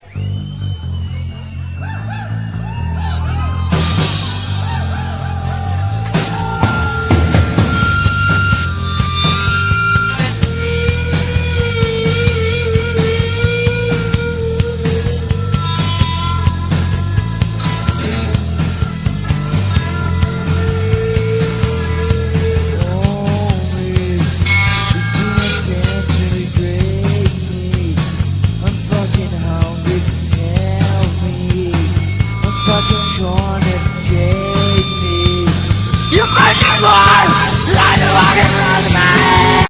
There are no known studio versions of this.